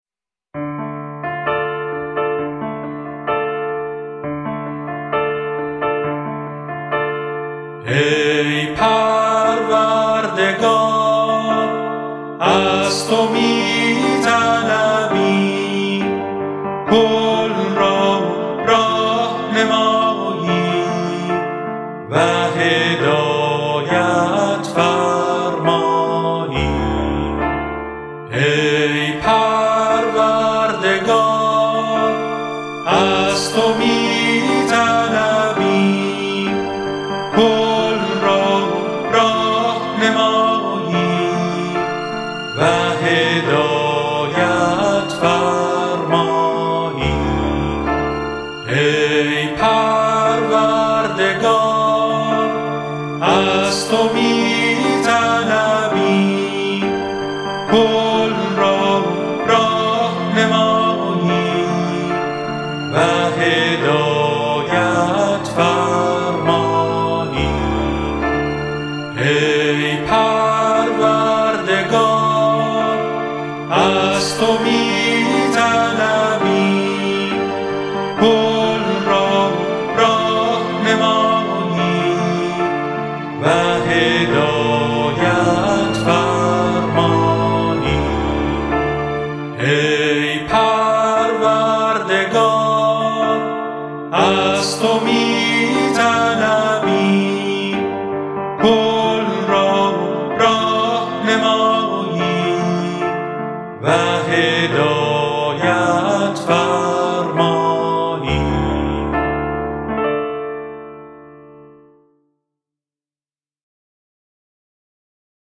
Download Track7.mp3 سایر دسته بندیها اذکار فارسی (آوازهای خوش جانان) 12876 reads Add new comment Your name Subject دیدگاه * More information about text formats What code is in the image?